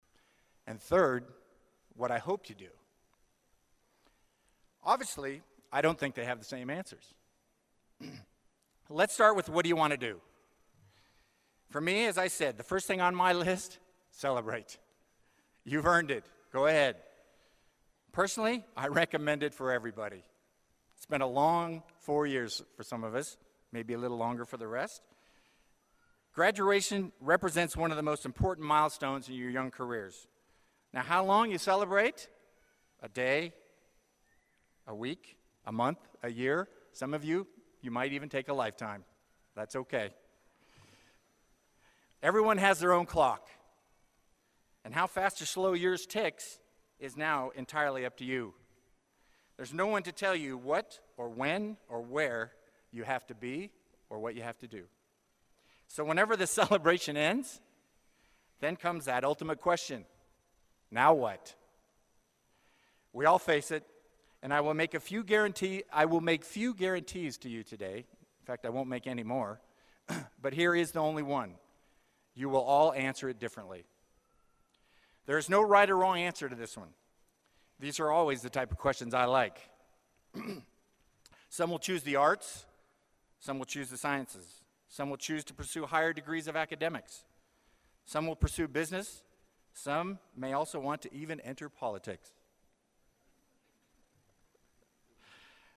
公众人物毕业演讲第375期